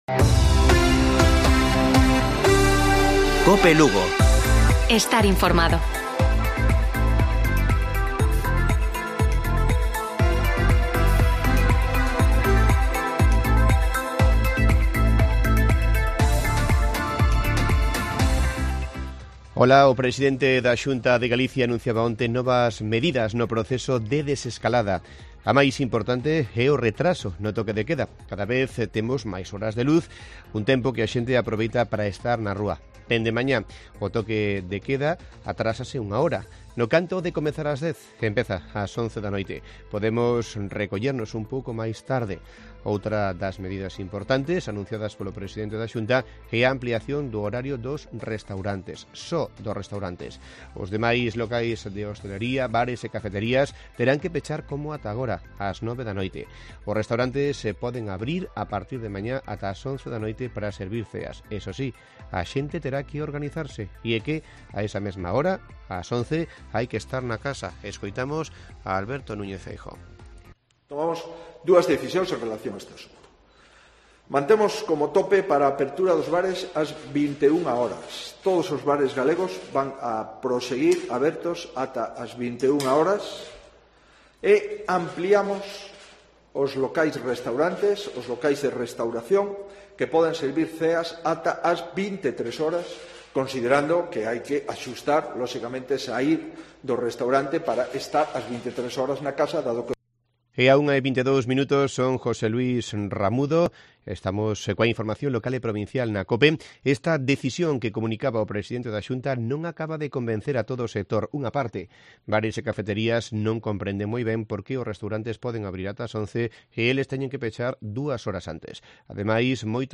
Informativo Provincial de Cope Lugo. 15 de abril. 13:20 horas